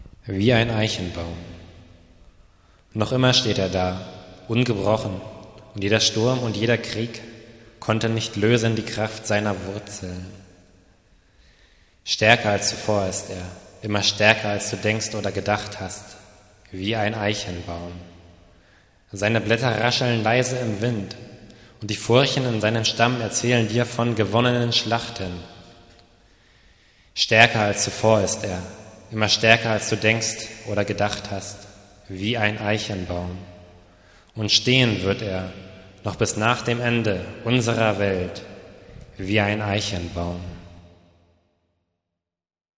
Hier gibt es einige improvisierte Lesungen meiner Texte zum Herunterladen im MP3-Format, in seltenen Fällen sind sie musikalisch untermalt. Es handelt sich um Lo-Fi Produktionen, wenn man sie überhaupt Produktionen nennen mag.